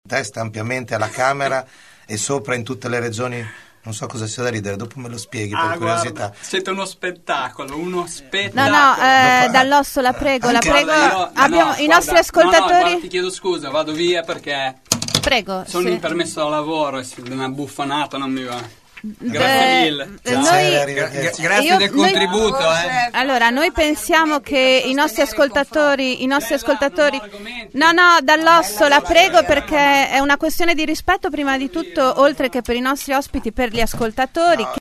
29 gen. – Matteo Dall’Osso, candidato del Movimento 5 stelle alla Camera, ha abbandonato la trasmissione a mezz’ora dal suo avvio in polemica con gli altri ospiti. Mentre Andrea De Maria, candidato del Pd, stava commentando i sondaggi che danno i democratici in discesa, Dall’Osso è scoppiato in una risata e ha aggiunto, rivolto agli altri ospiti: “Siete uno spettacolo”.